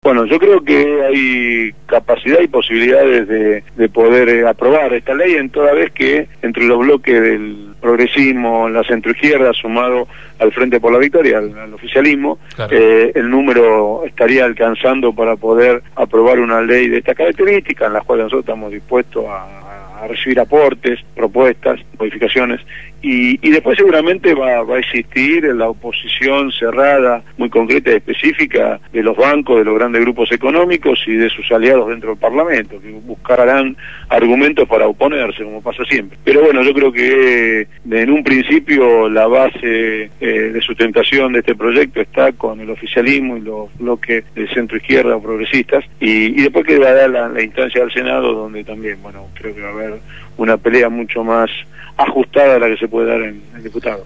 Ariel Basteiro presentó la nueva Ley de Entidades Financieras en Radio Gráfica